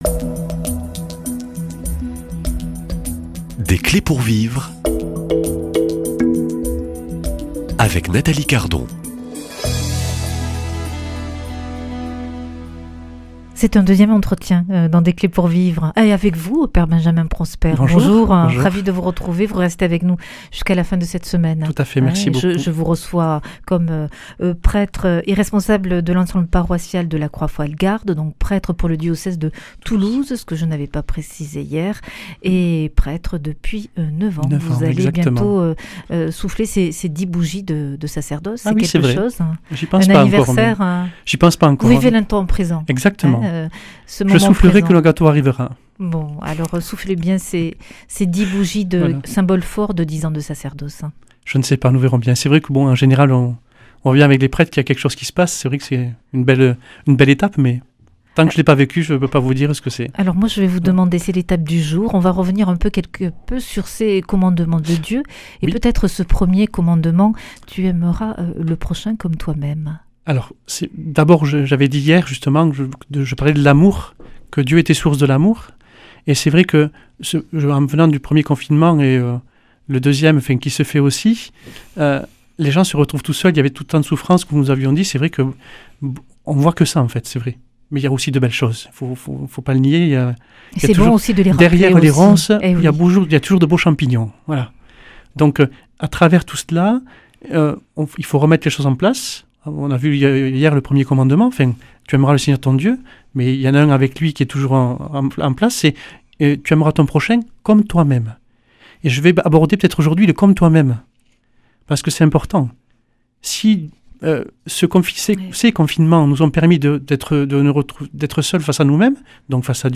[ Rediffusion ] Invité